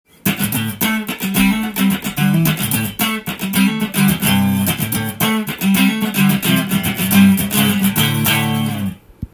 このようにカッティングしても最高の音がします。